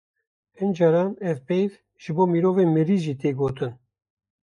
Pronounced as (IPA) /pɛjv/